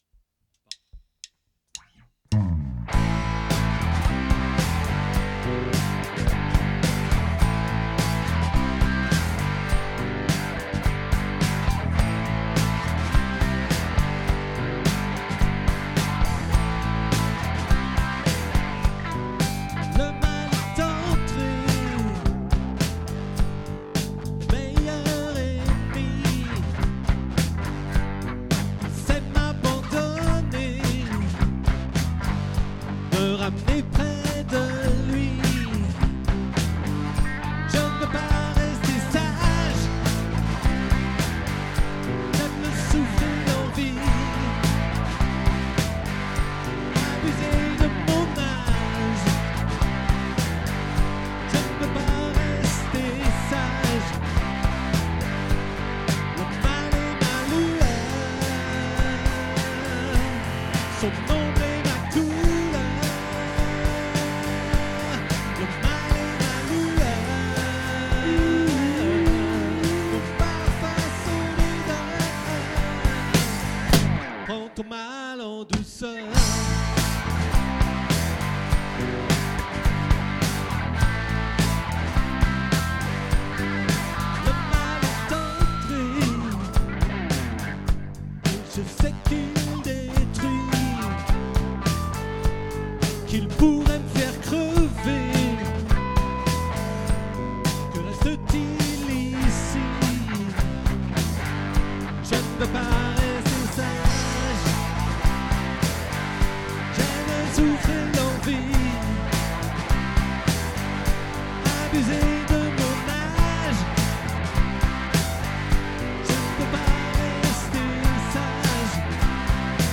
🏠 Accueil Repetitions Records_2025_09_01